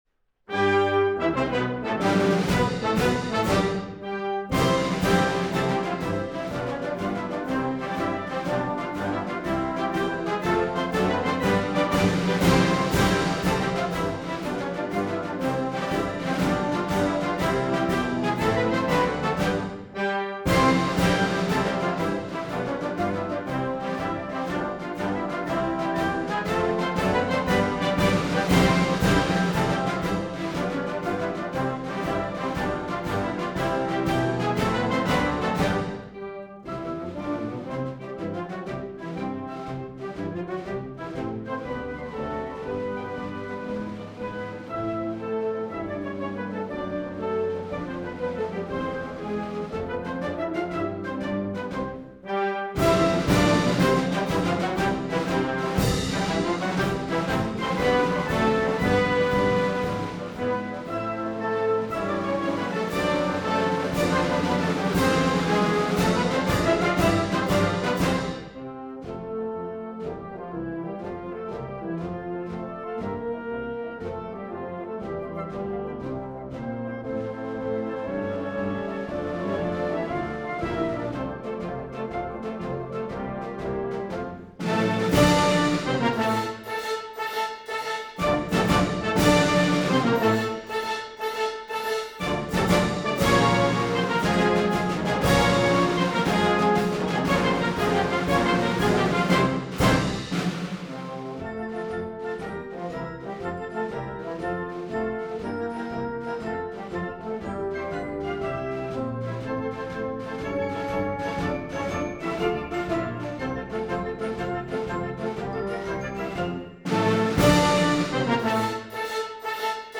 University of Nebraska March from The Complete Marches of John Philip Sousa: Vol. 6